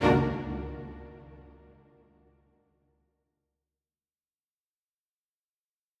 Strings Hit 4 Spiccato
Bring new life to your videos with professional orchestral sounds.
A spiccato is one of the shortest and fastest sounds that a string instrument can make.  In this sample, you hear four sections of four different instruments from the orchestra which are violins, violas, violoncellos and double basses.
Strings-Hit-4-Spiccato.wav